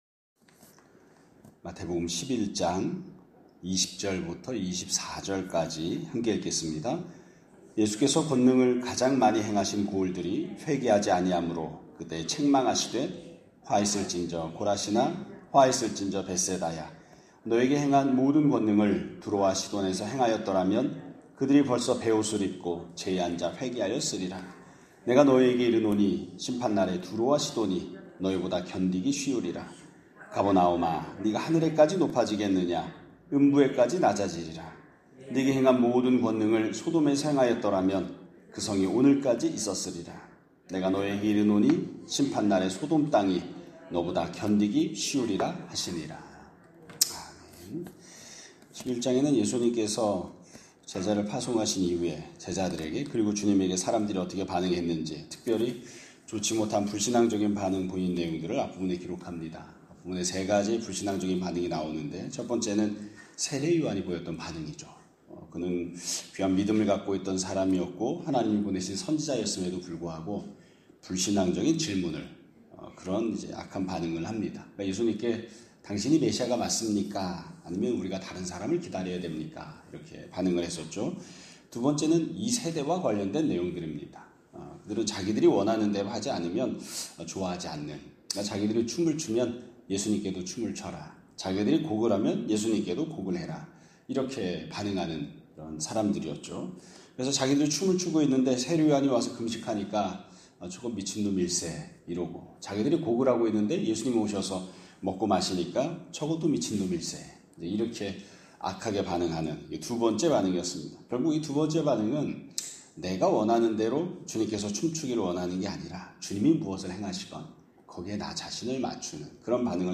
2025년 8월 29일 (금요일) <아침예배> 설교입니다.